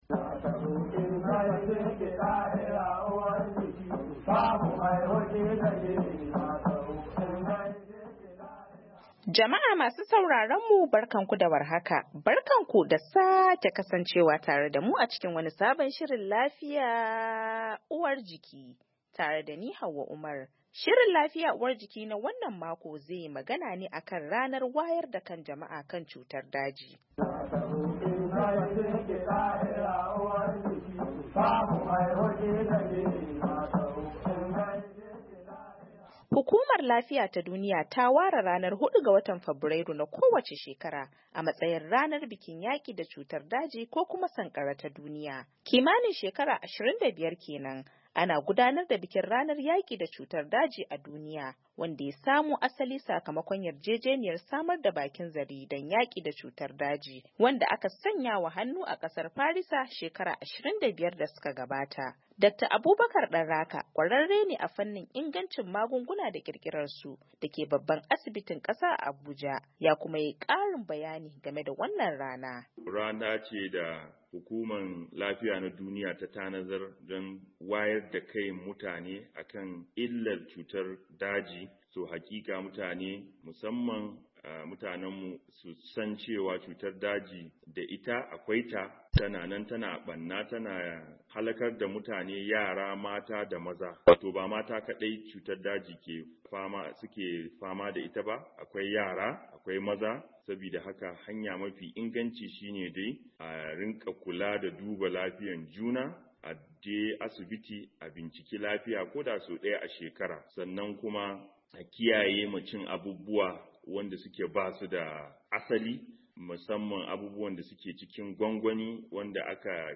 A shirin Lafiya na wannan makon mun yi duba kan ranar wayar da kai game da cutar daji, inda muka tattauna da kwararru kan maganin cutar daji, da wasu daga cikin kungiyoyin kula da masu fama da cutar da kuma wadanda suka yi fama da cutar.